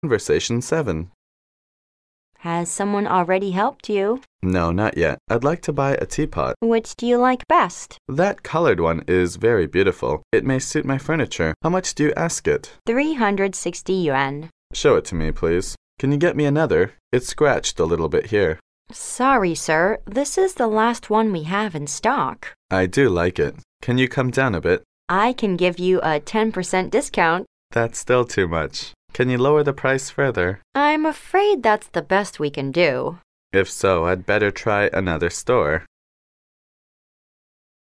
Conversation 7